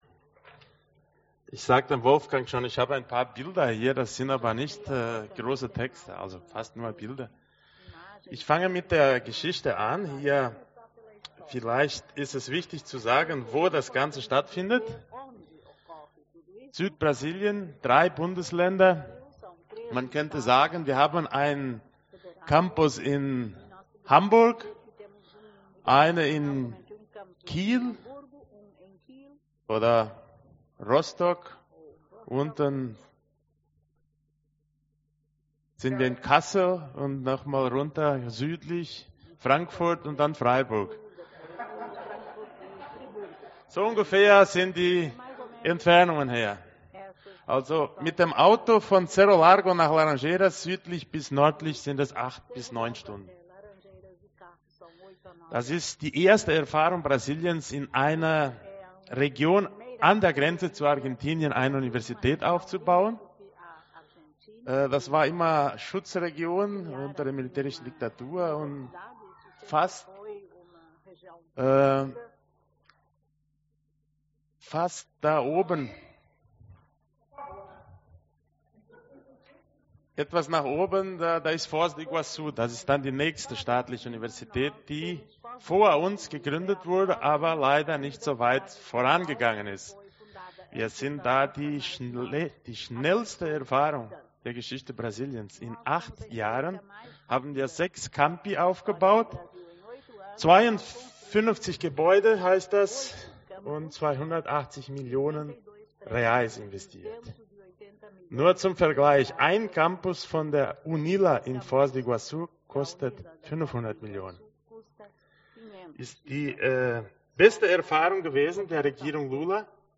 Diskussion: